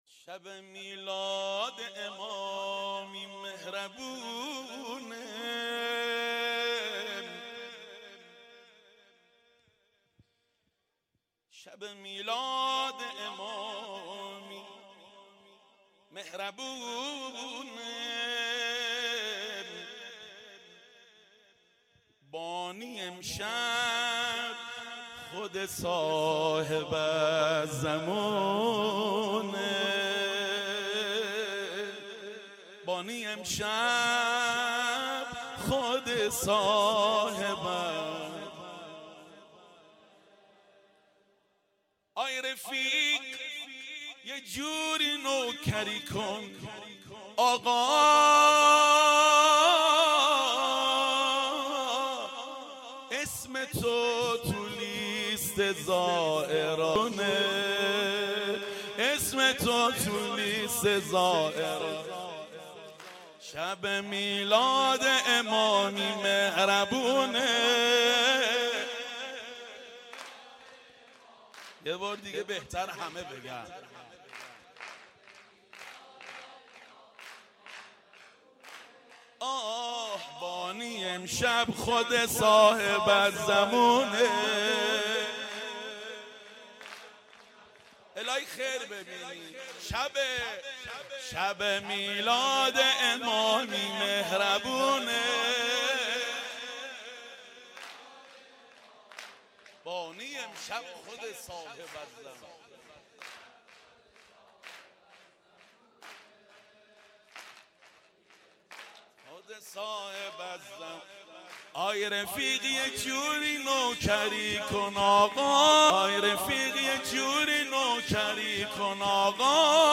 جشن بزرگ میلاد امام حسن عسکری«ع» و مراسم هفتگی قرائت زیارت آل یاسین